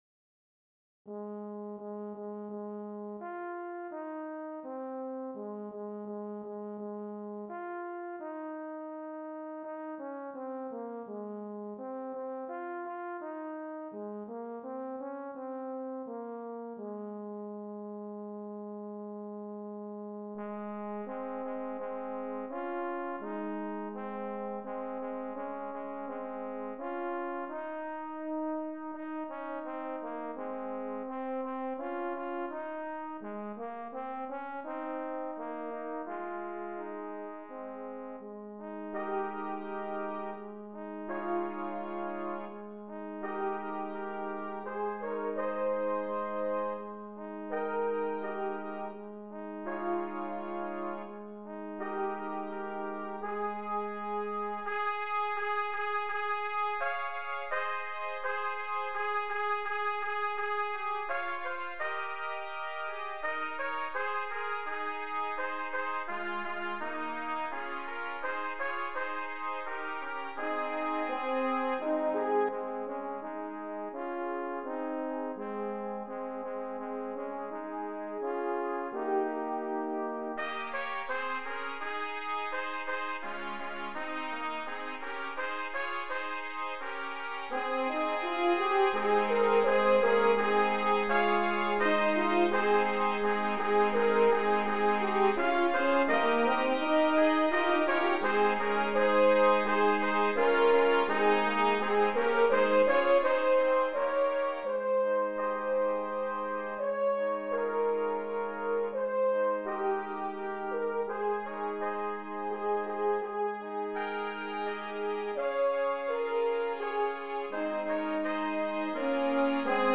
traditional English Christmas carol
This piece is flowing and smooth, quiet and reflective.